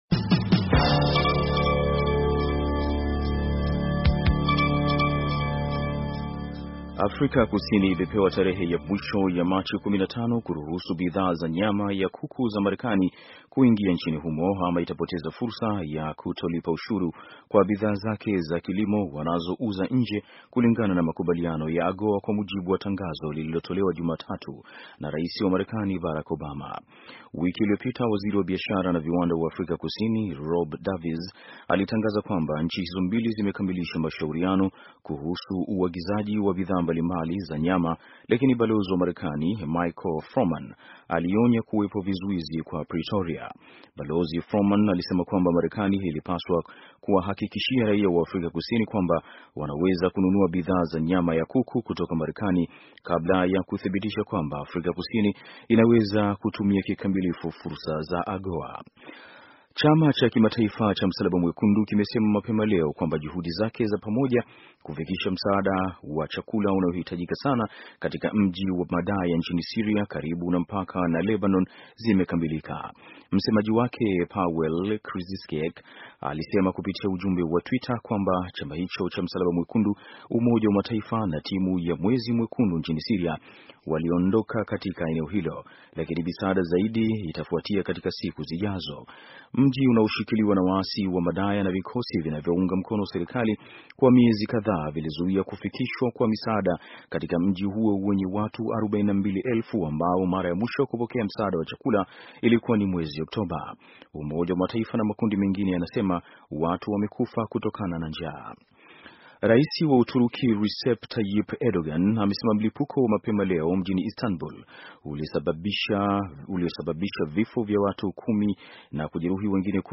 Taarifa ya habari - 6:04